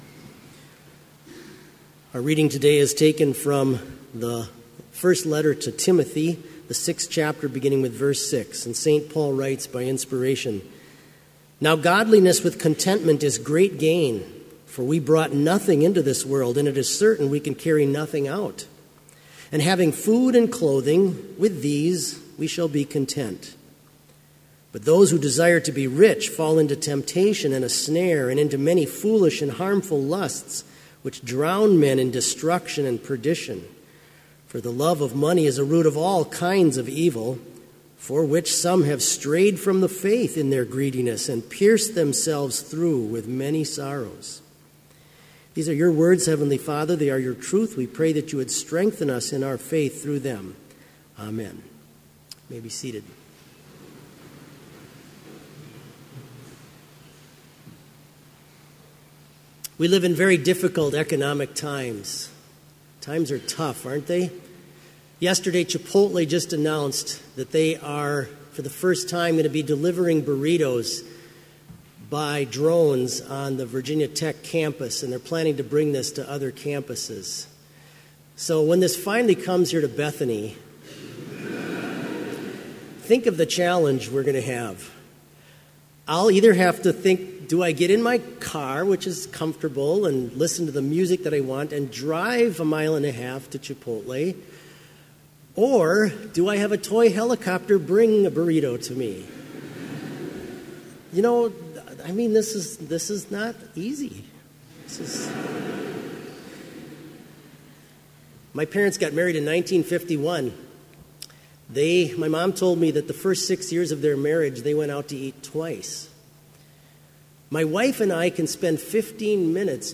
Complete service audio for Chapel - September 9, 2016